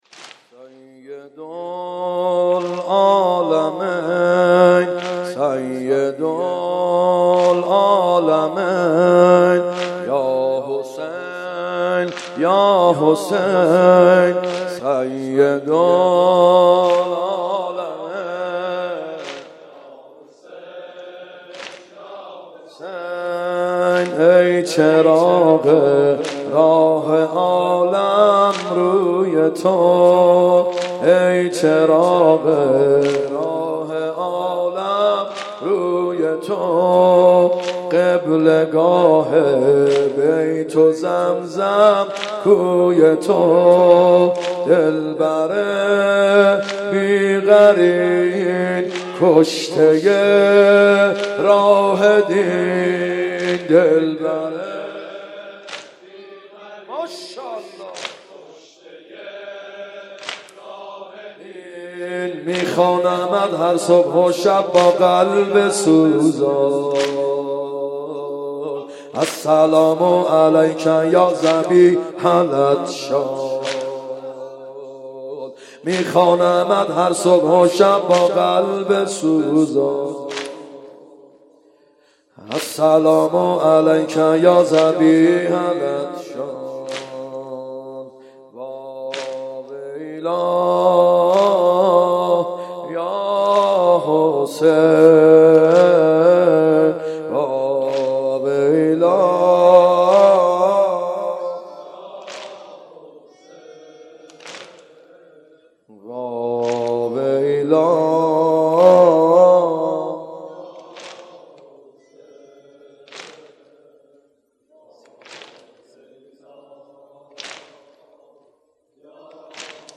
محرم 93( هیأت یامهدی عج)
گلچین محرم الحرام 1393 اشتراک برای ارسال نظر وارد شوید و یا ثبت نام کنید .